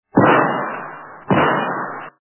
Звук ружья - Выстрел Звук Звуки Звук рушниці - вистріл
» Звуки » другие » Звук ружья - Выстрел
При прослушивании Звук ружья - Выстрел качество понижено и присутствуют гудки.